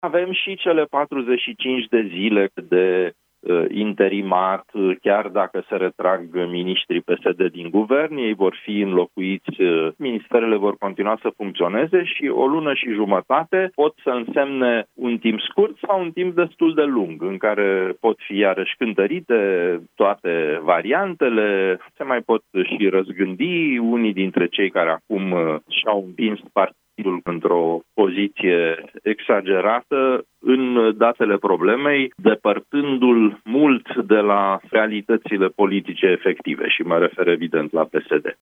Analist politic